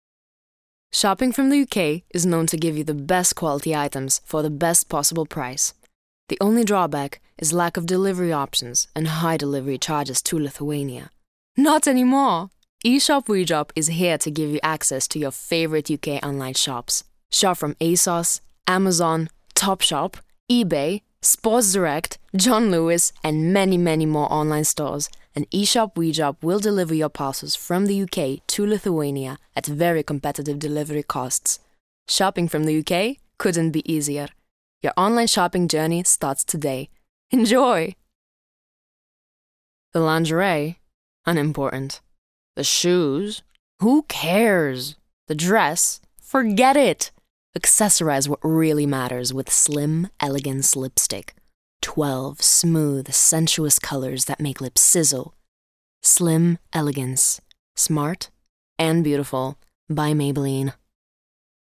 Lithuanian, Female, 20s-30s